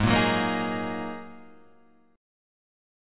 G# 7th Suspended 2nd